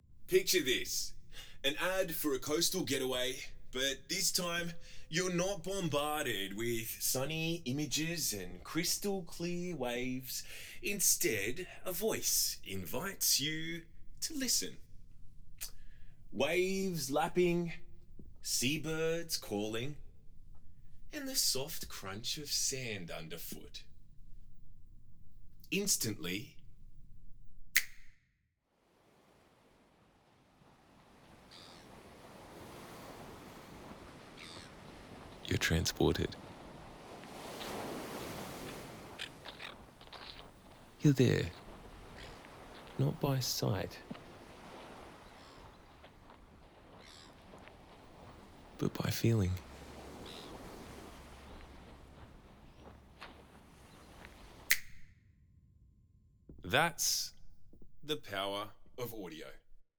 Mediaweek-ImmersiveAudioMock-REV-1.wav